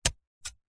Звуки сада
Садовая мотыга с деревянной ручкой для удара по почве и траве